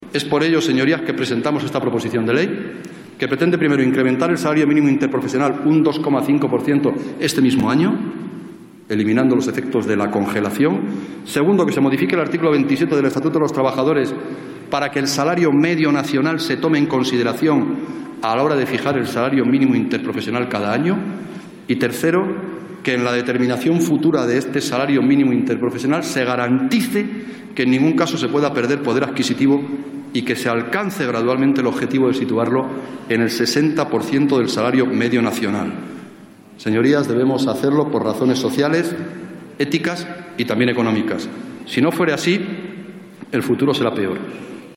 Fragmento de la intervención de Jesús Caldera en el Pleno del Congreso defendiendo una proposición de ley para aumentar el salario mínimo interprofesional 13-05-14